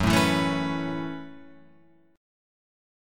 F#m11 chord